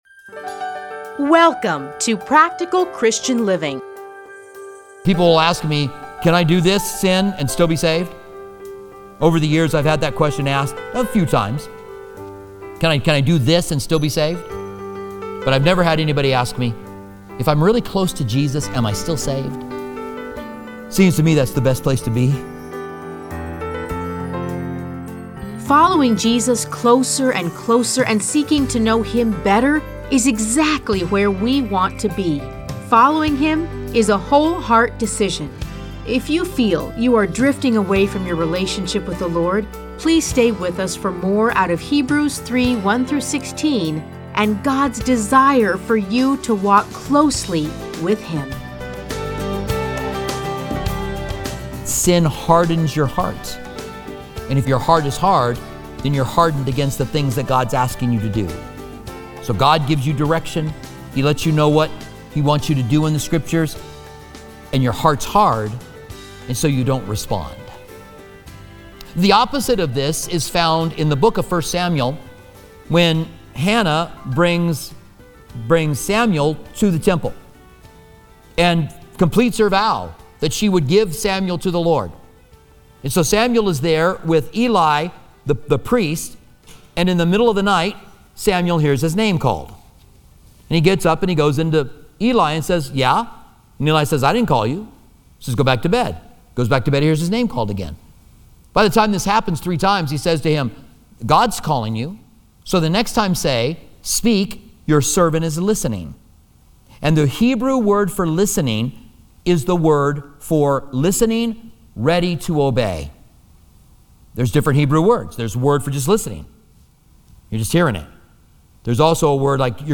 Listen to a teaching from Hebrews 3:1-16.